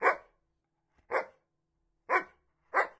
cf_dog_barking.ogg